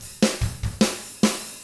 146ROCK F1-R.wav